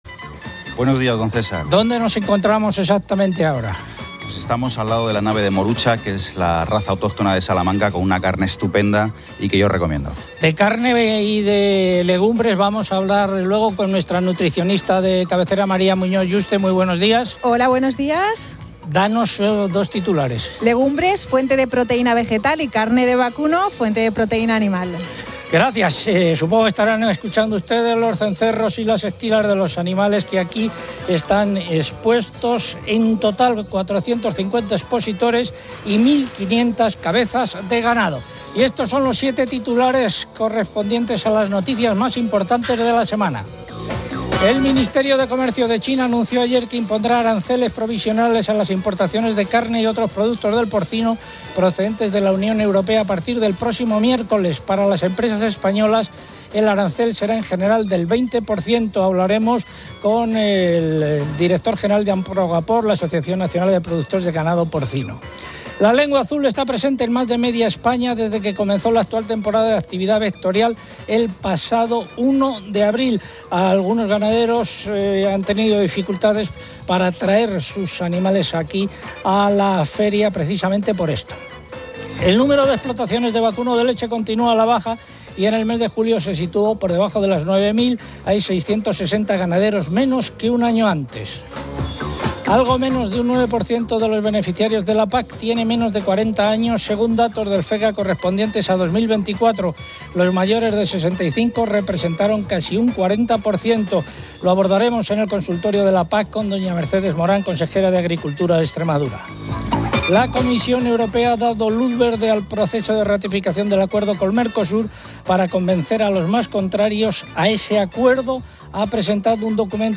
El programa radiofónico desde Salamaq aborda diversos temas agrícolas y ganaderos.